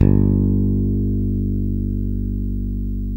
Index of /90_sSampleCDs/Roland L-CD701/BS _Jazz Bass/BS _Jazz Basses